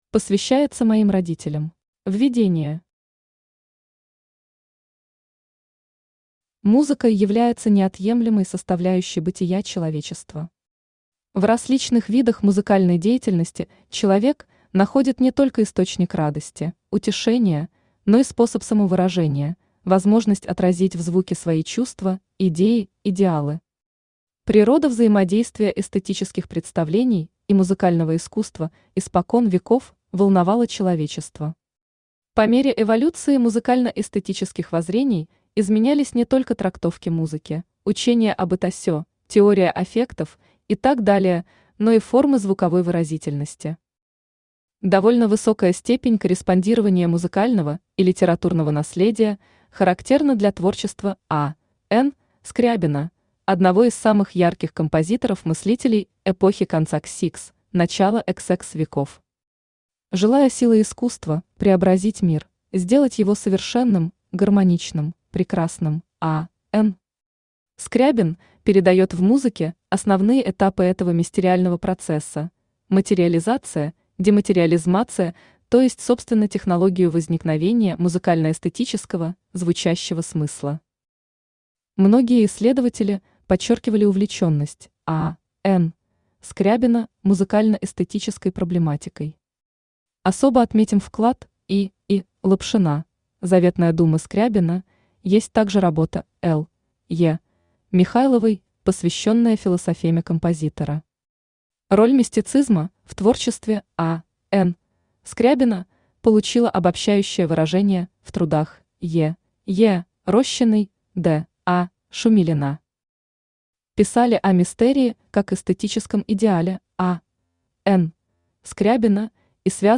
Aудиокнига Музыкально-эстетическая концепция А.Н. Скрябина Автор Анна Ивановна Маслякова Читает аудиокнигу Авточтец ЛитРес.